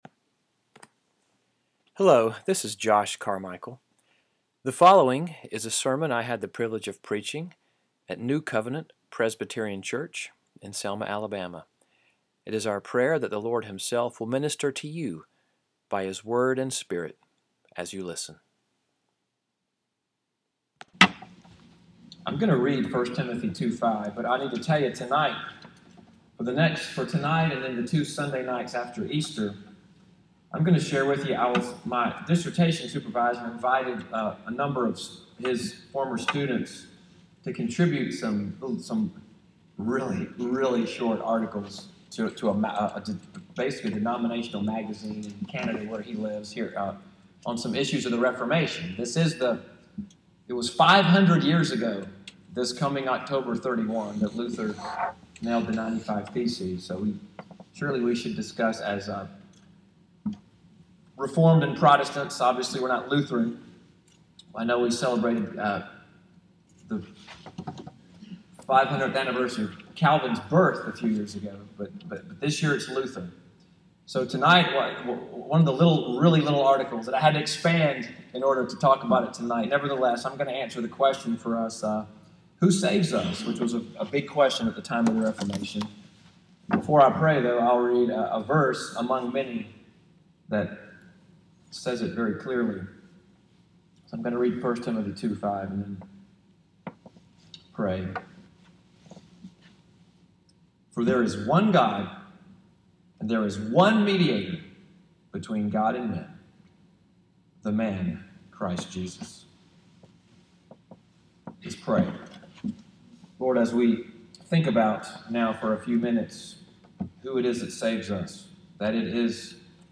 EVENING WORSHIP at NCPC, April 9, 2017.
This evening’s teaching, more a Bible study, doctrinal lesson, and church history lesson than a sermon, briefly answers the question, “Who Saves Us?”